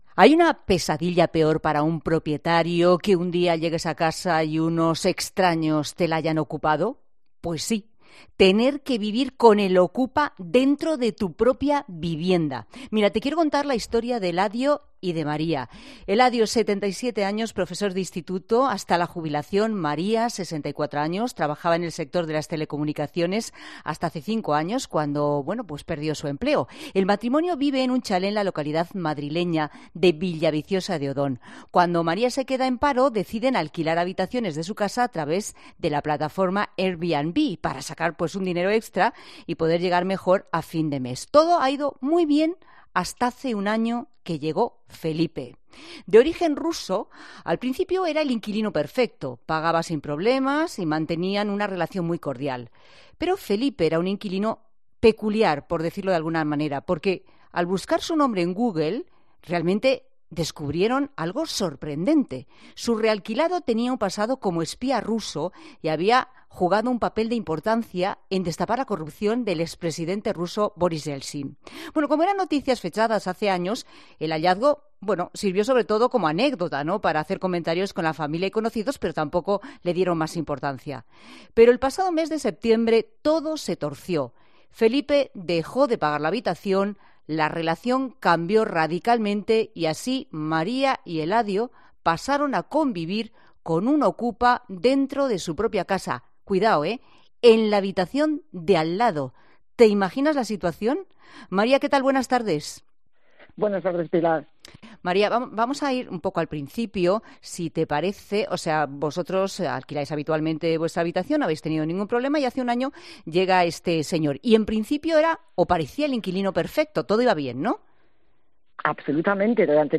ha entrevistado